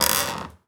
chair_frame_metal_creak_squeak_05.wav